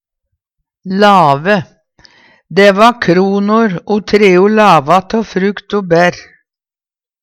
lave - Numedalsmål (en-US)